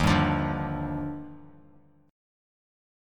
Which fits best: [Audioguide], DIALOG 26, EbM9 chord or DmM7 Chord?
EbM9 chord